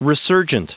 Prononciation du mot resurgent en anglais (fichier audio)
Prononciation du mot : resurgent